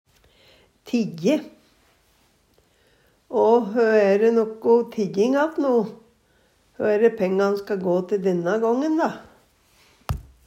tijje - Numedalsmål (en-US)